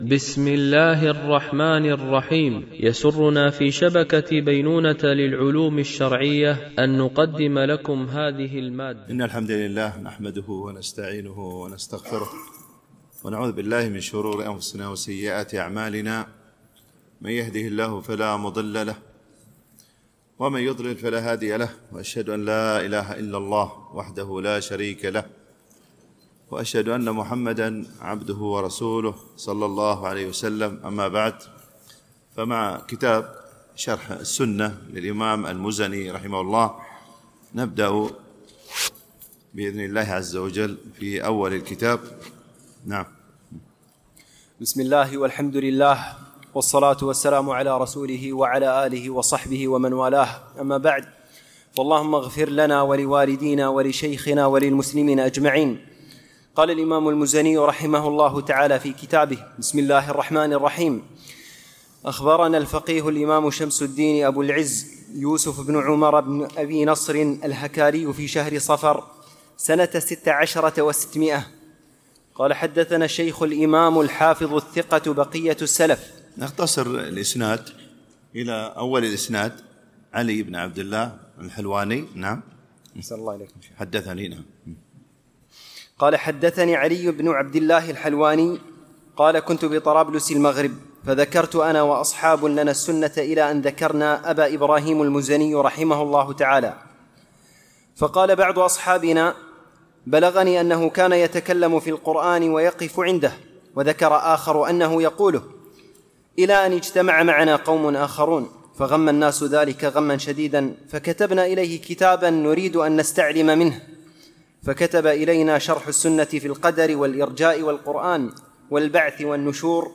الدورة: دورة الإمام مالك العلمية الحادية عشرة، بدبي